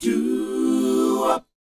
DOWOP D 4E.wav